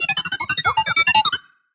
beeps1.ogg